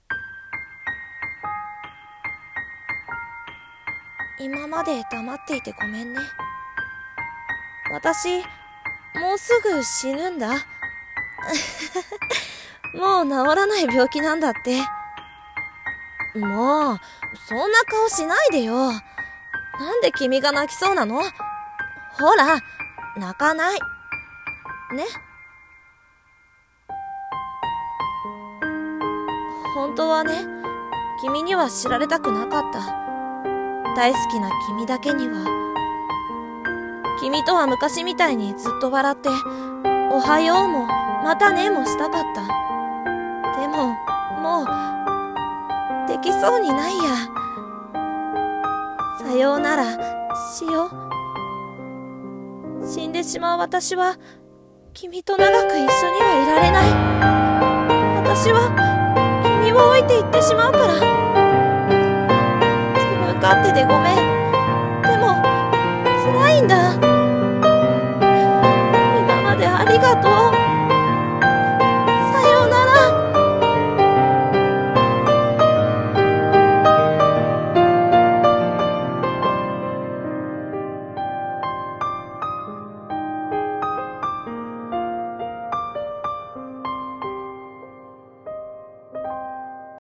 声劇 さようなら。